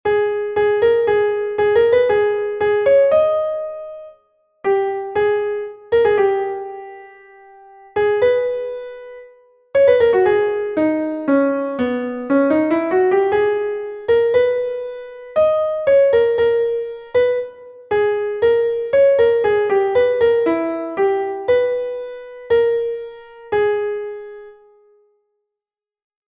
Entoación a capella
entonacioncapela10.3.mp3